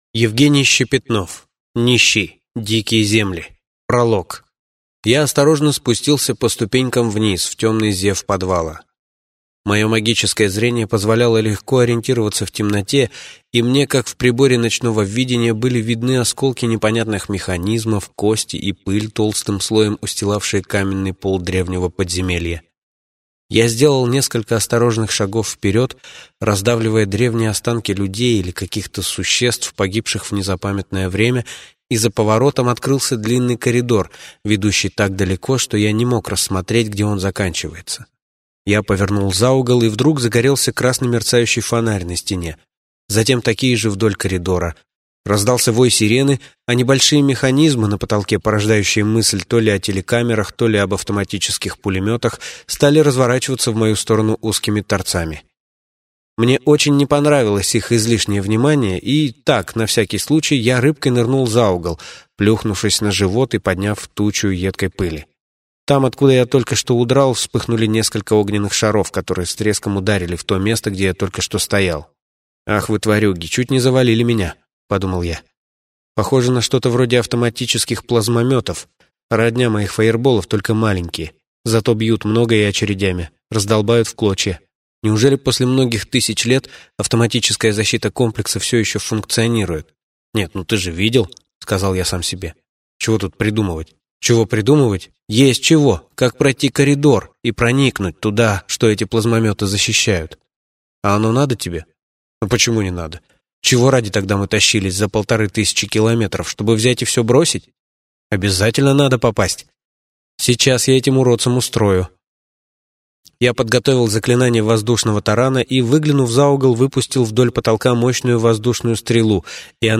Аудиокнига Нищий. Дикие земли | Библиотека аудиокниг